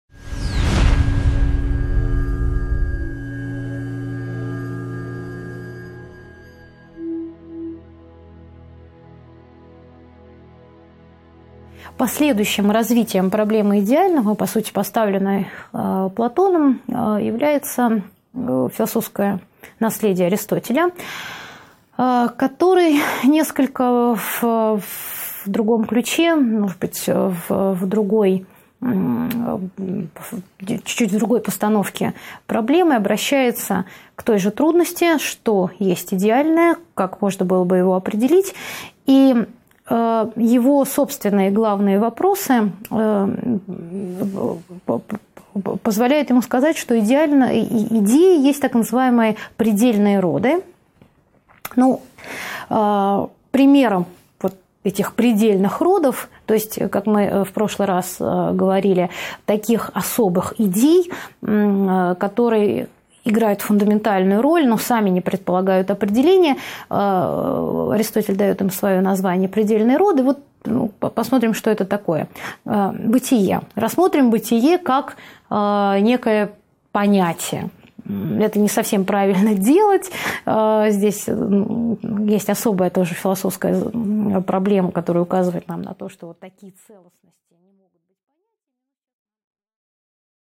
Аудиокнига 2.9 Категории Аристотеля. Трансценденталии | Библиотека аудиокниг